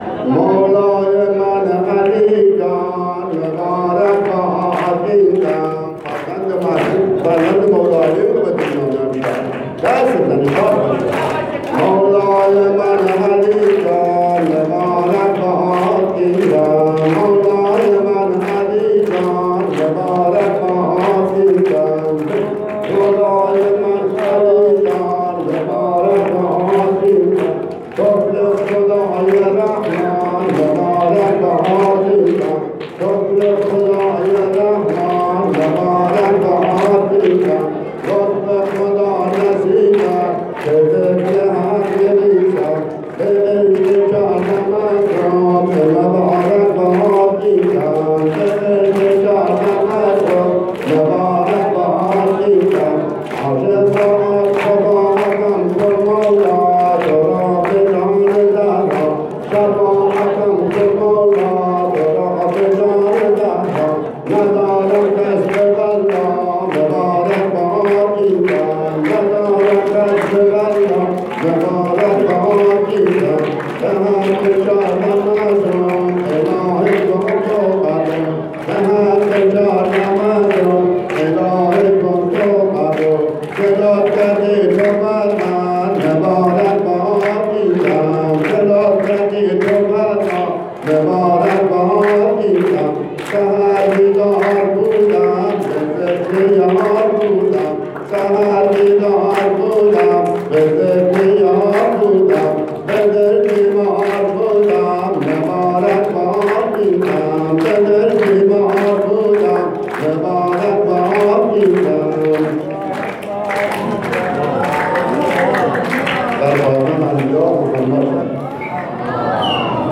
مراسم جشن نیمه شعبان۹۷